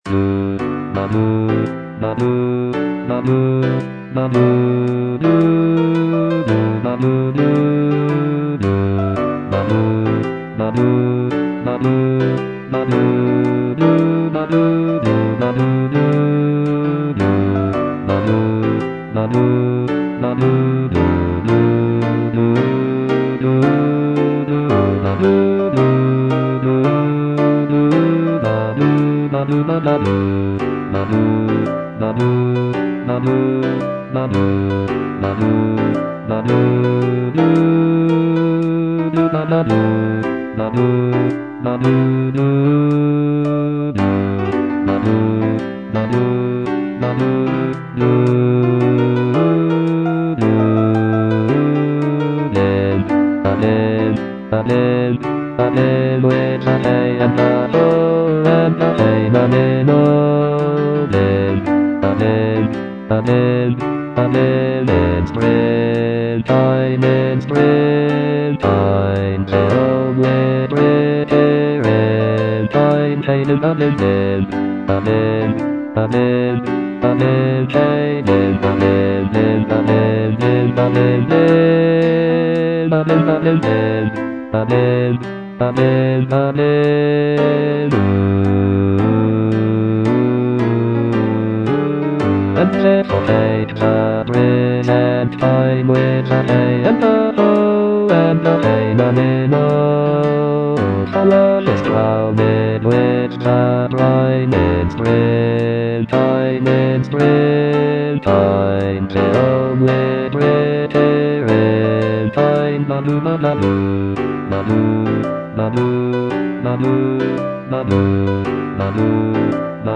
Bass (Voice with metronome)
is a choral work
Written in a lively and upbeat style